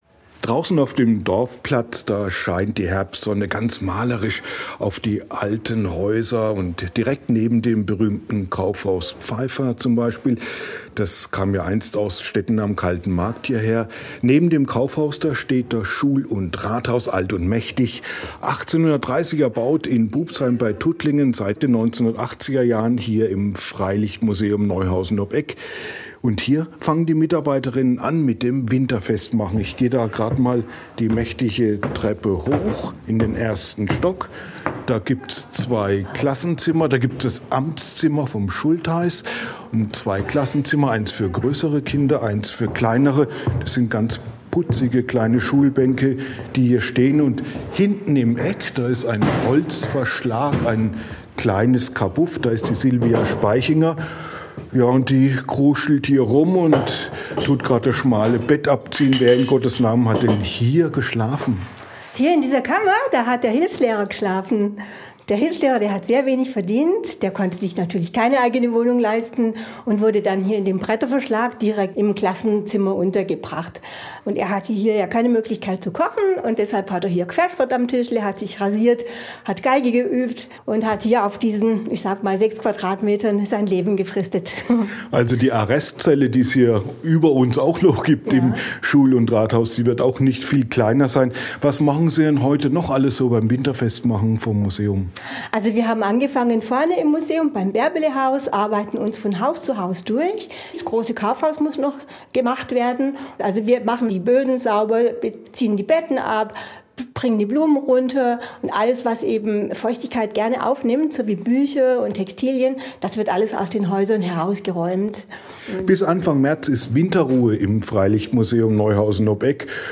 Im sonnigen Herbstlicht plätschert der Dorfbrunnen im Freilichtmuseum Neuhausen ob Eck (Kreis Tuttlingen) gemütlich vor sich hin.